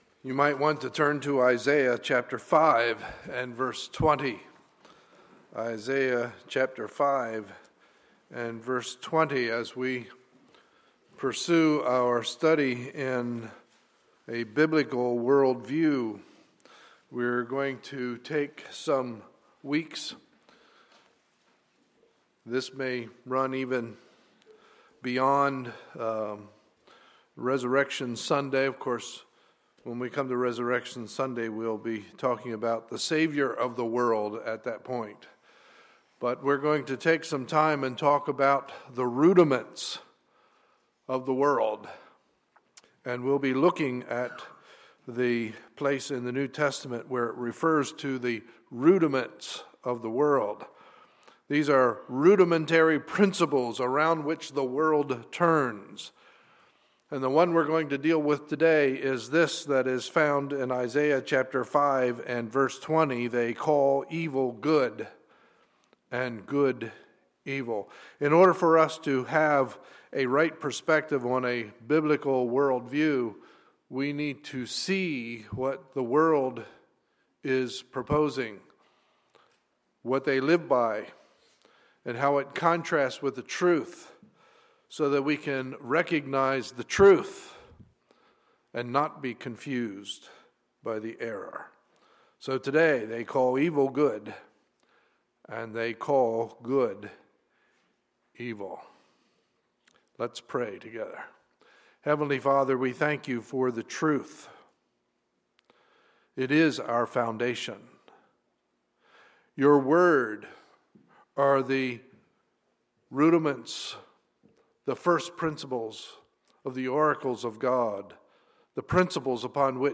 Sunday, April 15, 2012 – Morning Message